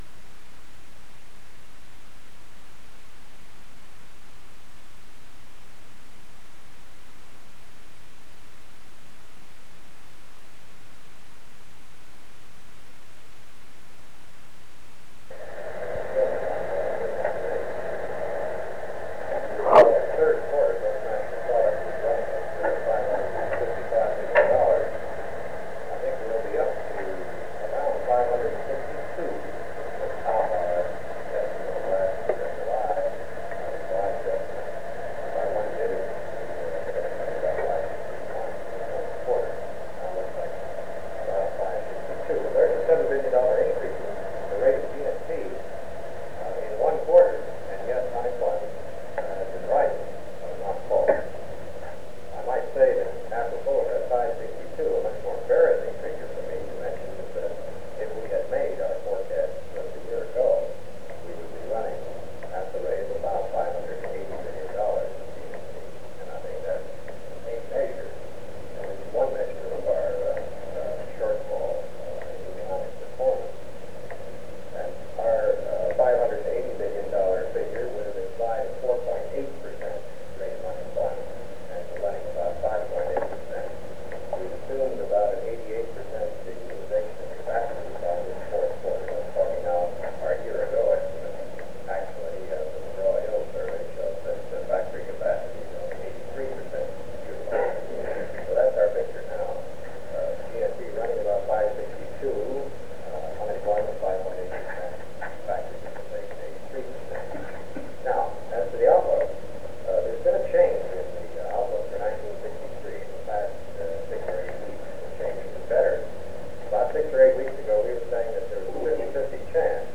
Cabinet Meeting
Secret White House Tapes | John F. Kennedy Presidency Cabinet Meeting Rewind 10 seconds Play/Pause Fast-forward 10 seconds 0:00 Download audio Previous Meetings: Tape 121/A57.